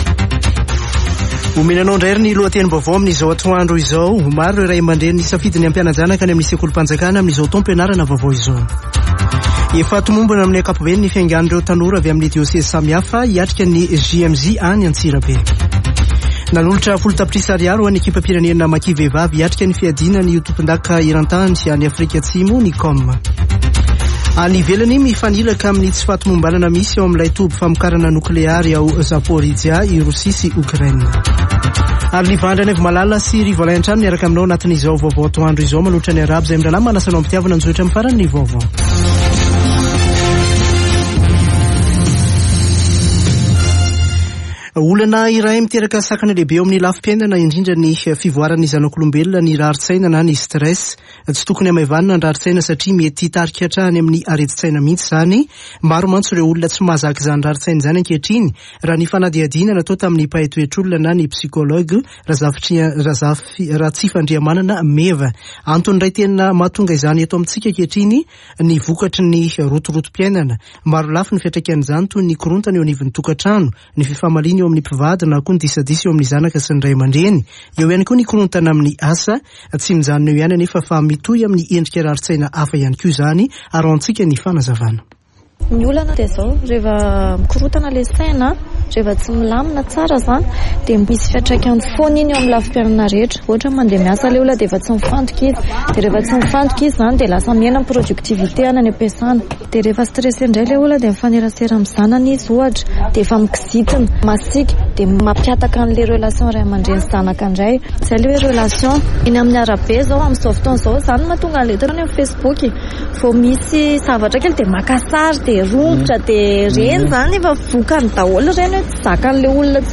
[Vaovao antoandro] Zoma 26 aogositra 2022